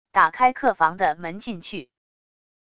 打开客房的门进去wav音频